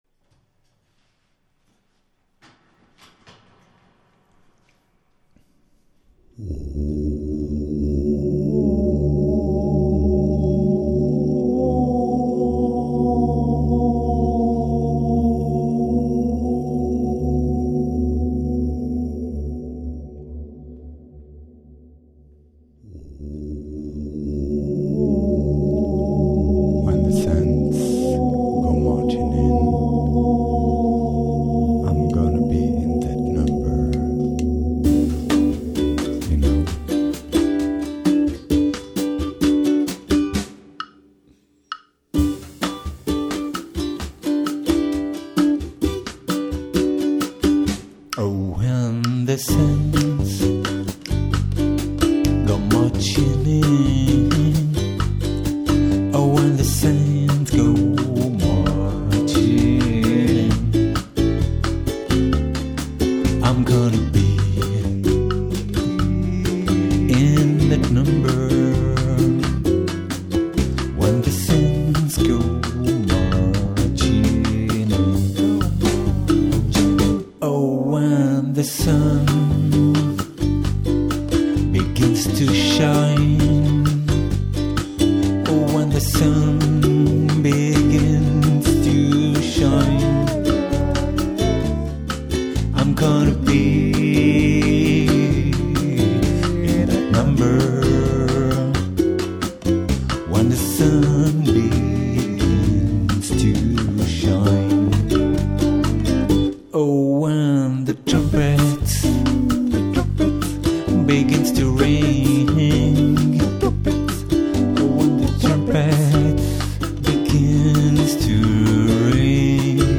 Puisque parait-il, Noà«l est la période des saints, je vous fait part de mon dernier "travail", j´ai enregistré hier et aujourd´hui un immense standard du jazz traditionnel : "When the saints", vous pourrez aller l´écouter ici :